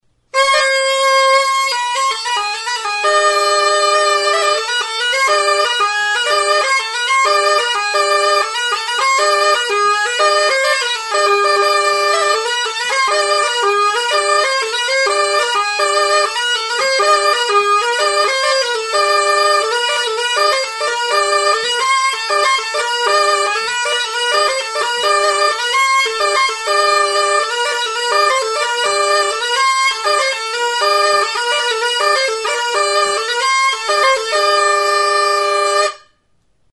Aerófonos -> Lengüetas -> Simple (clarinete)
Grabado con este instrumento.
EUROPA -> EUSKAL HERRIA
Klarinete bikoitza.